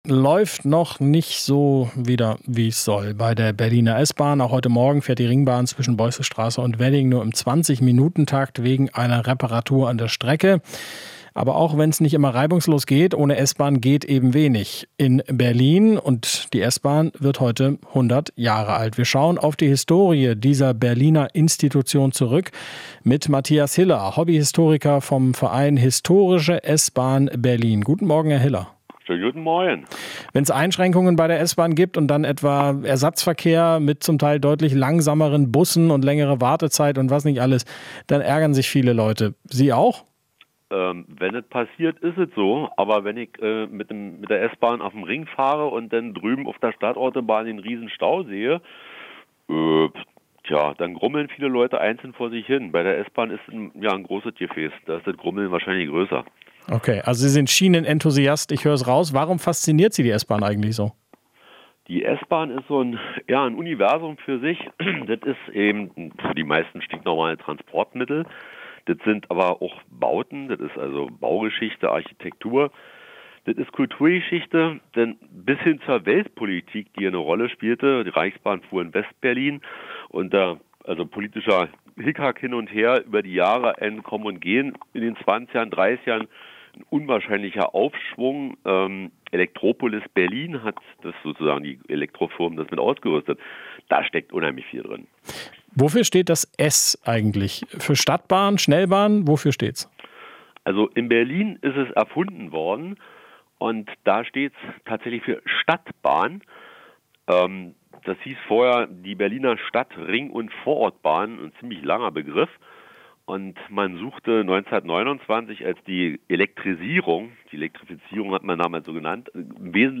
Interview - "Die S-Bahn ist ein Universum für sich"